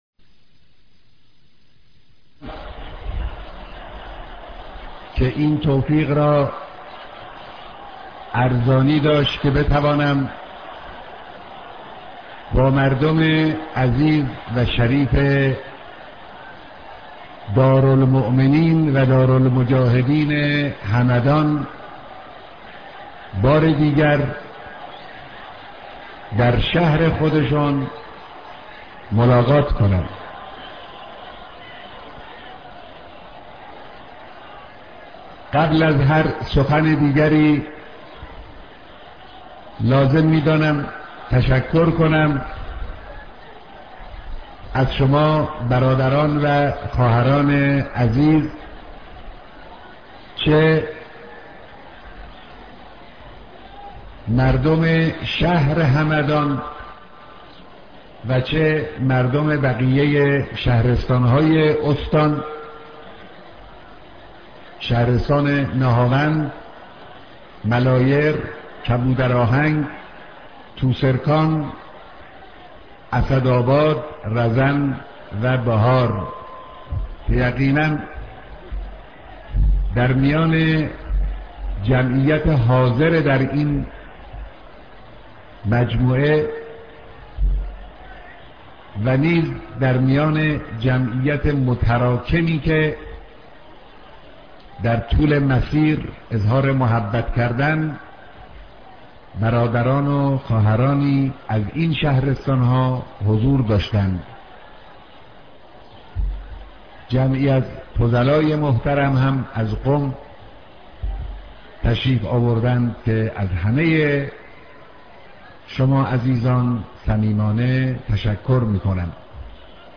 بيانات در اجتماع بزرگ مردم همدان